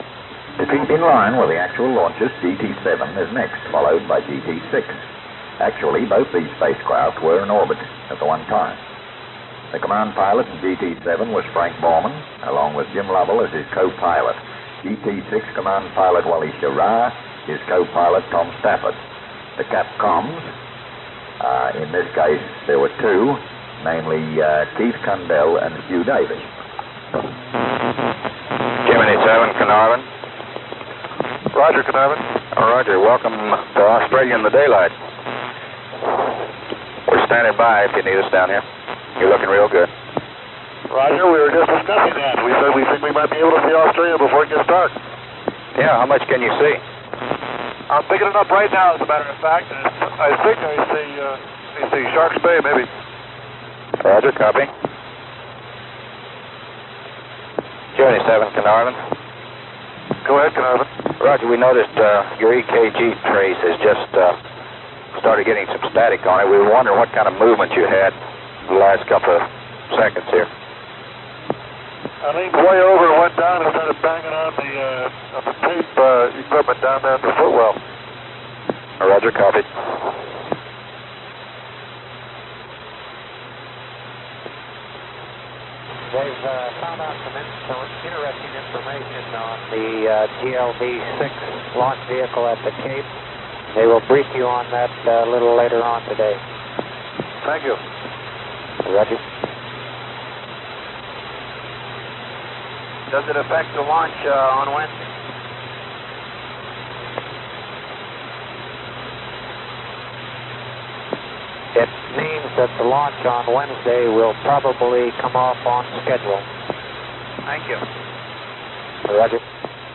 At the end of the Gemini series, a compilation tape of Carnarvon clips from all the manned missions (with the exception of GT10), was produced.